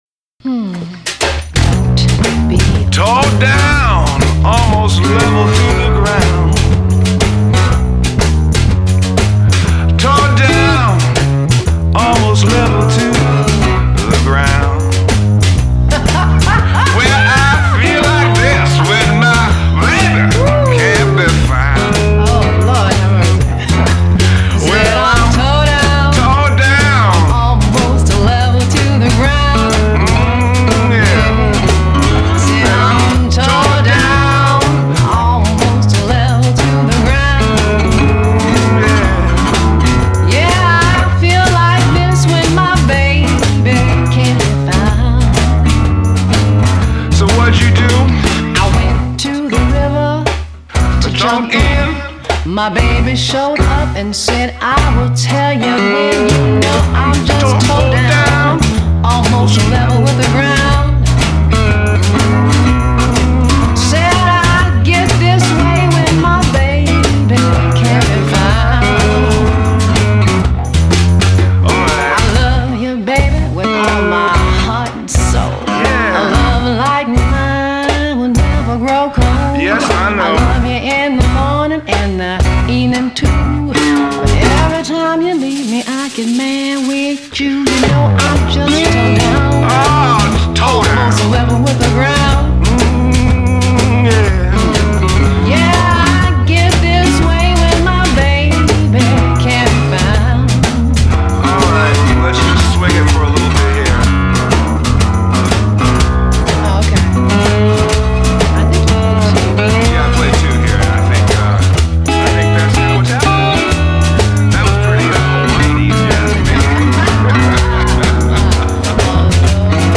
Жанр: Blues-Rock